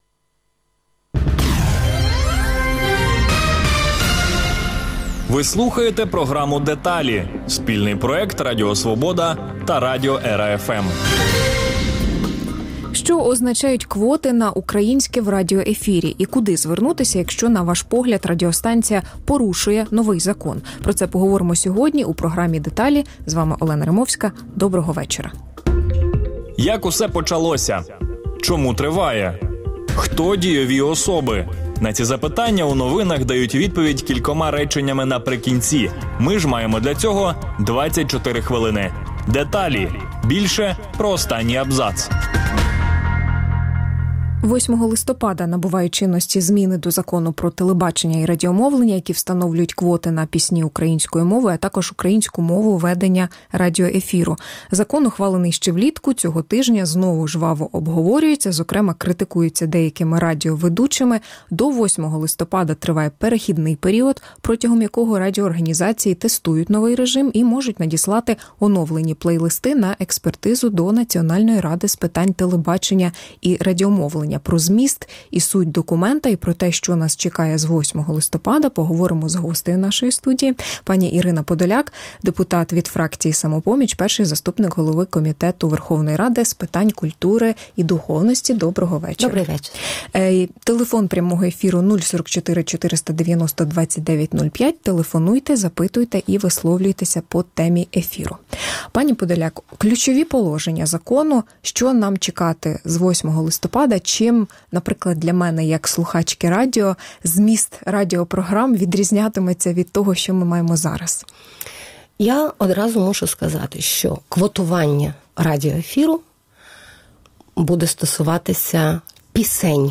Гість: Ірина Подоляк, перший заступник голови Комітету Верховної Ради України з питань культури і духовності |